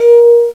GLASS4.WAV